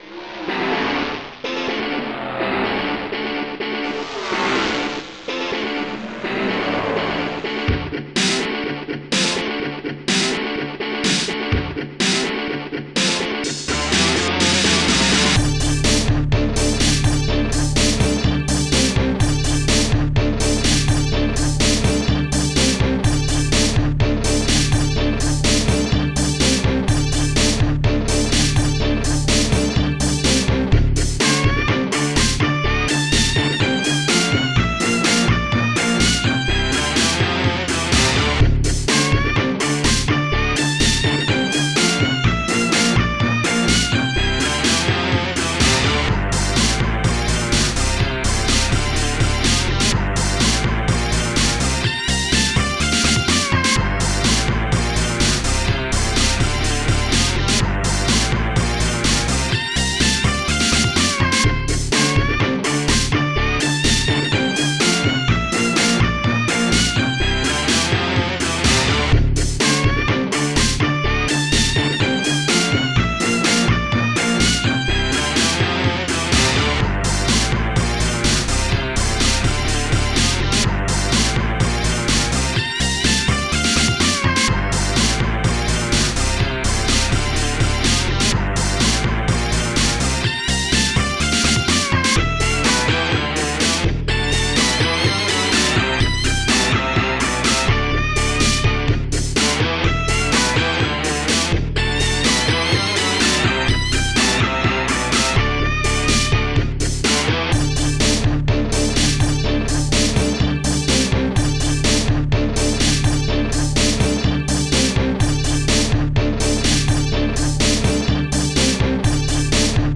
Star Tracker/StarTrekker Module  |  1992-07-06  |  95KB  |  2 channels  |  44,100 sample rate  |  4 minutes, 51 seconds
......l rock song for
all you guitar lovers
HardRock.mp3